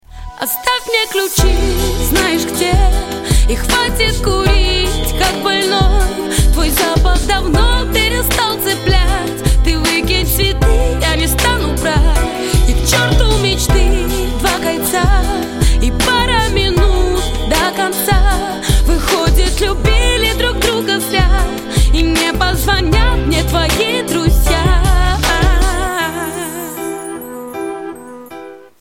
Рэп, Хип-Хоп, R'n'B [75]